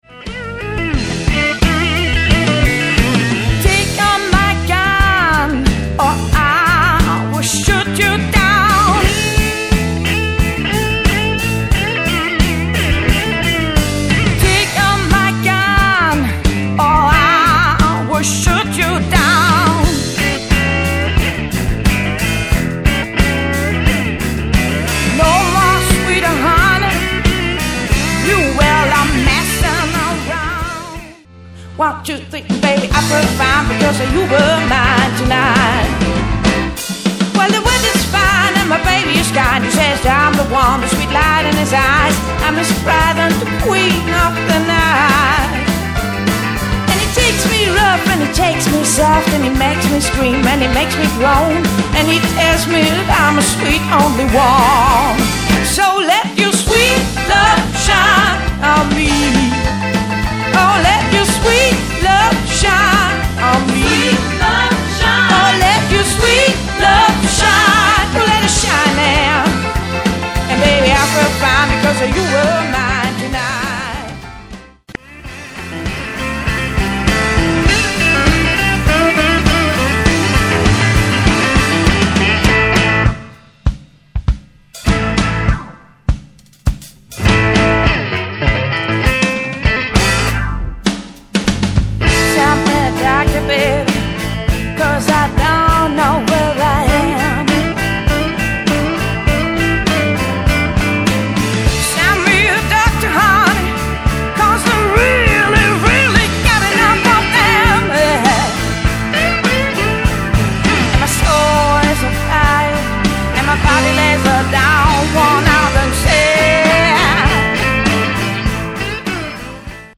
recorded August 2009 in Stantonville/ Memphis Tenneessee
electric guitar
keyboard
backing vocals